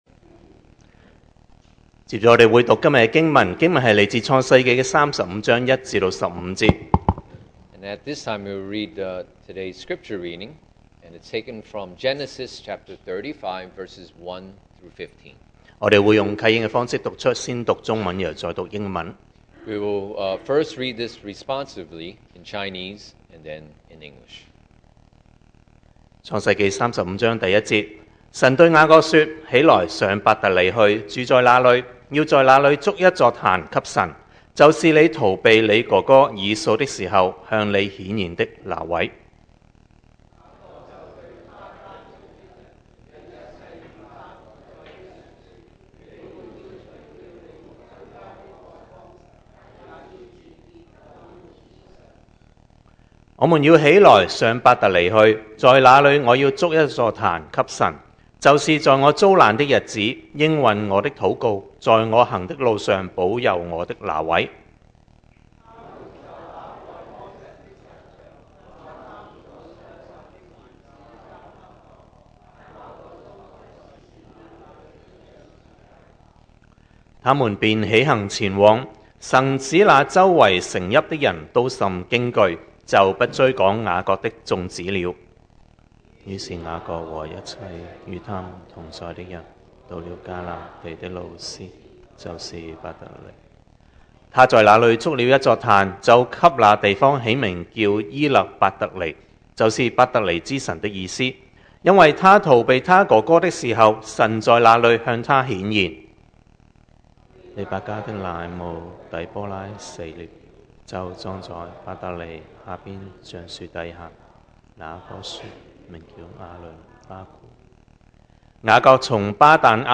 2024 sermon audios
Service Type: Sunday Morning